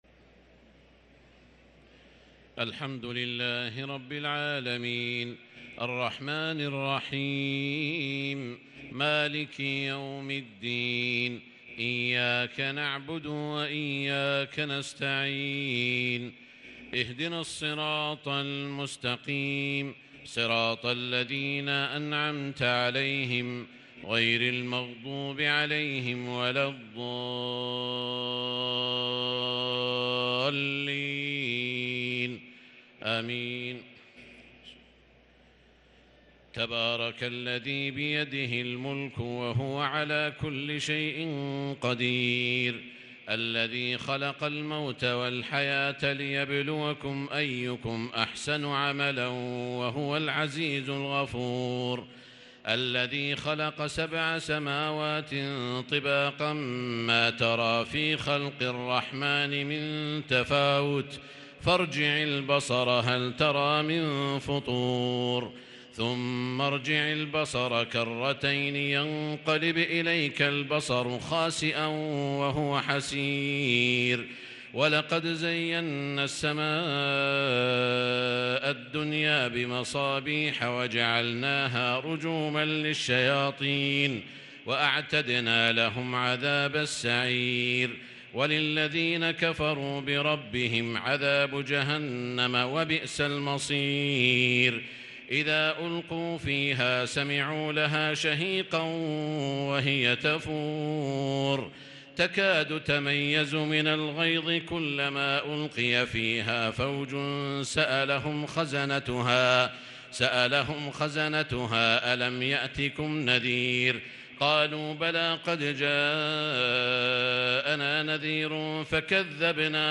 صلاة التراويح ليلة 28 رمضان 1443 للقارئ سعود الشريم - الثلاث التسليمات الاولى صلاة التهجد